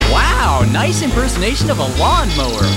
Wow Lawnmower RC STUNT COPTER
wow-lawnmower.mp3